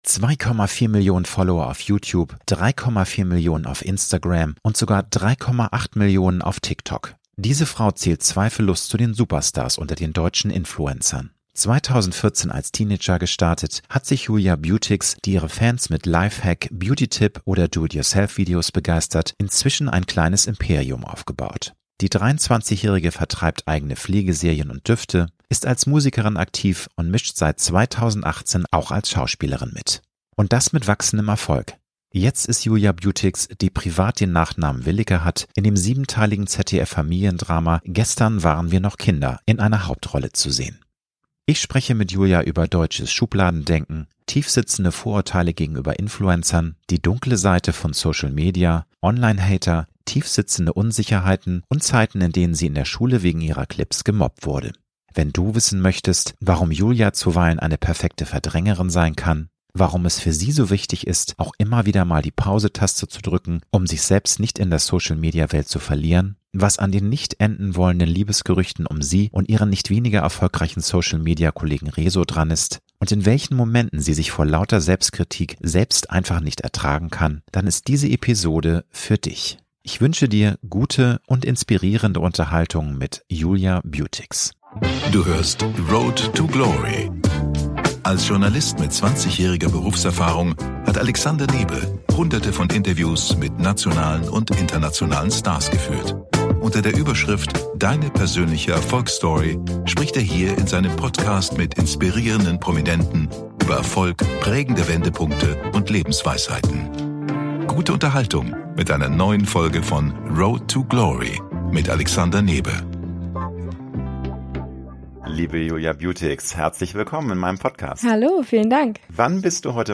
Ich spreche mit Julia über deutsches Schubladendenken, tiefsitzende Vorurteile gegenüber Influencern, die dunkle Seite von Social Media, Online Hater, tiefsitzende Unsicherheiten und Zeiten, in denen sie in der Schule wegen ihrer Clips gemobbt wurde.